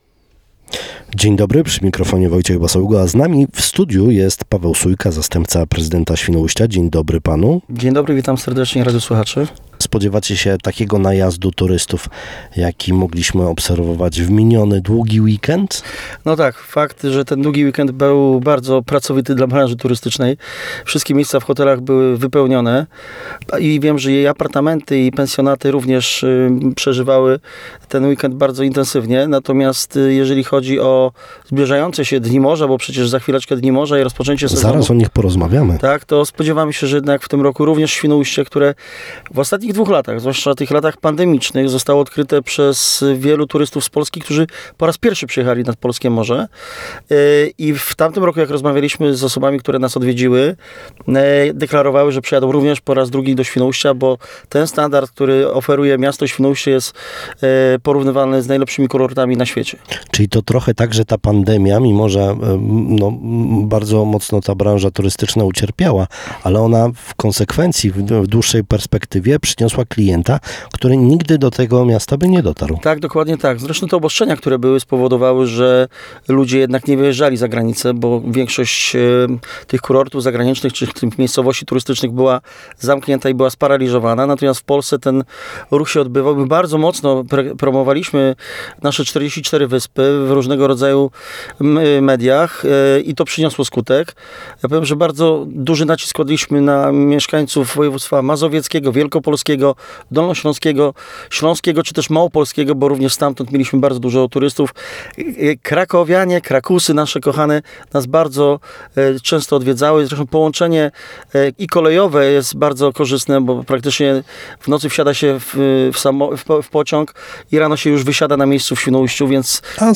Naszym gościem Rozmowy Dnia był dziś Paweł Sujka, zastępca prezydenta Świnoujścia.